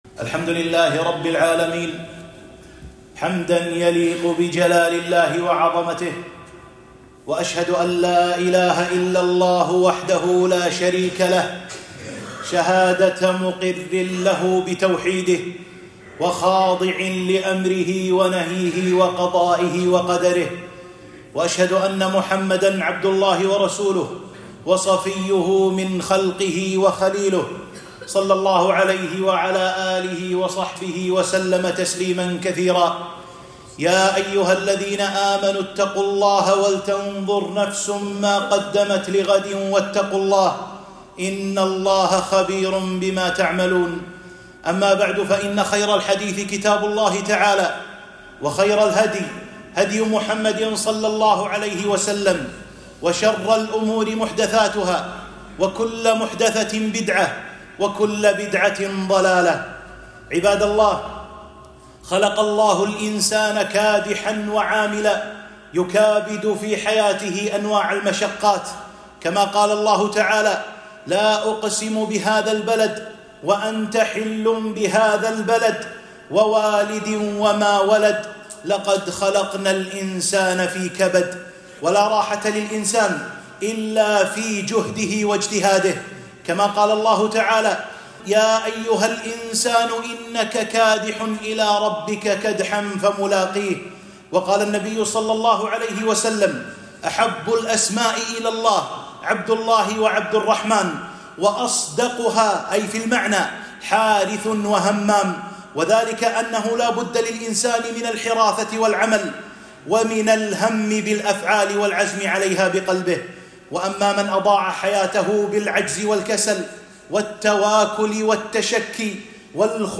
خطبة - آداب وأحكام الإجازة والنزهة